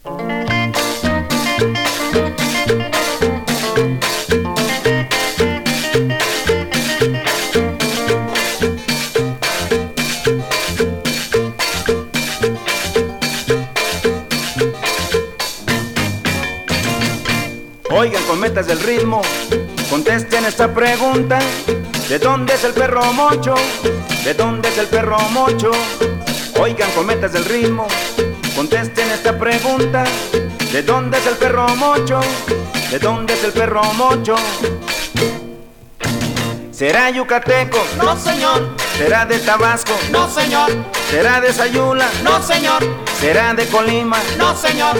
Latin, Cumbia, Boogaloo　USA　12inchレコード　33rpm　Mono/Stereo